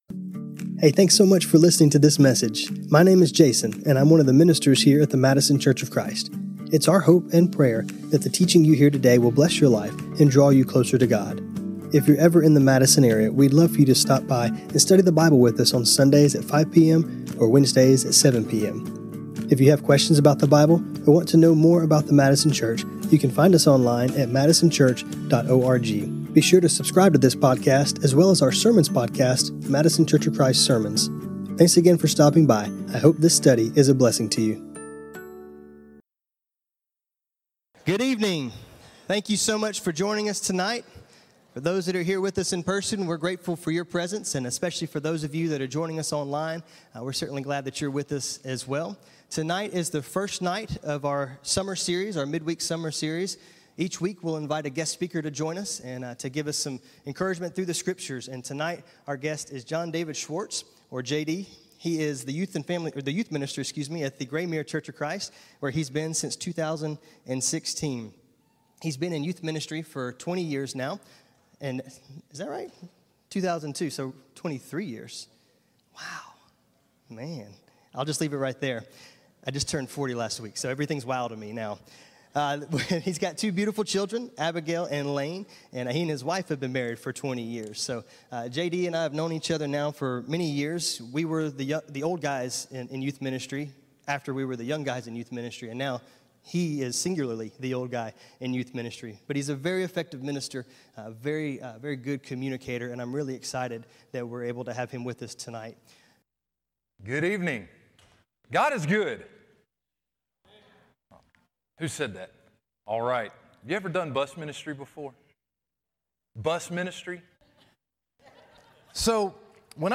Each week we will have a guest speaker bringing us a powerful message from the Word of God.